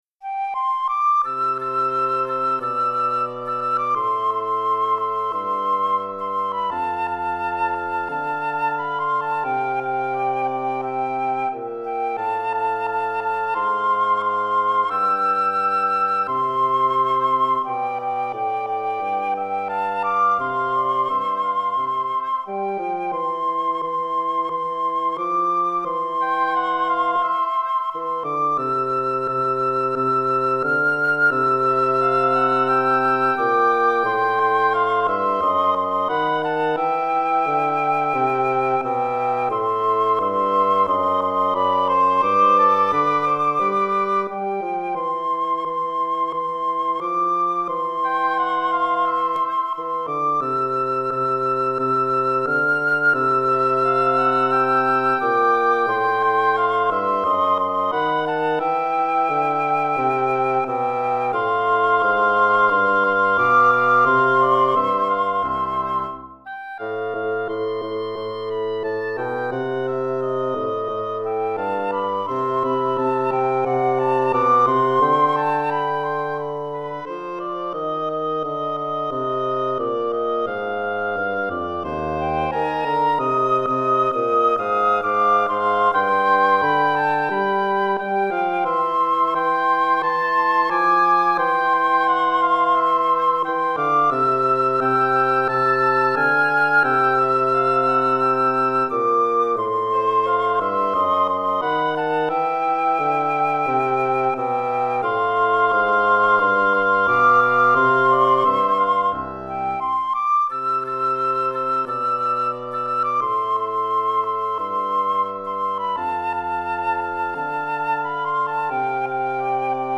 Recueil pour Musique de chambre - Flûte 1 Hautbois 1 Basson